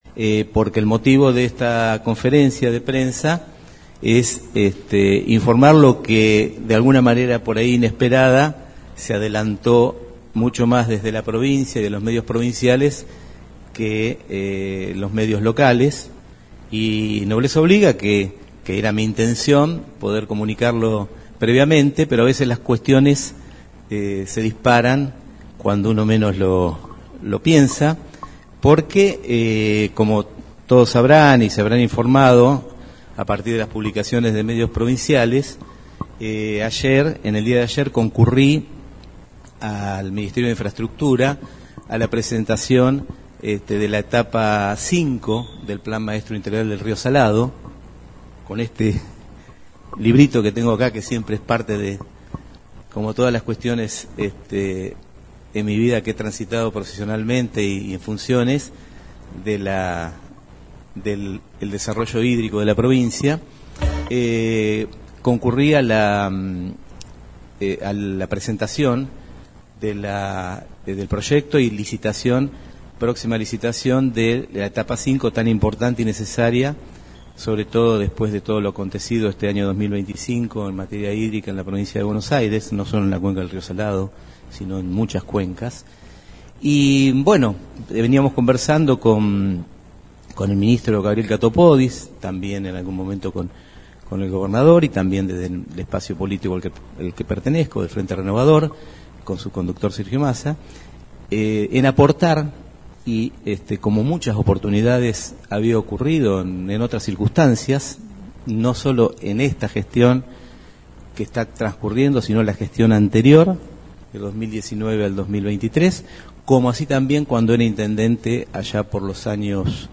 En conferencia de prensa que tuvo lugar este martes en el salón rojo municipal el jefe comunal confirmó que fue convocado por la cartera que encabeza Gabriel Katopodis para asumir una función clave en el área de Recursos Hídricos, como lo indicaron varios medios platenses.
Audio Conferencia de Prensa: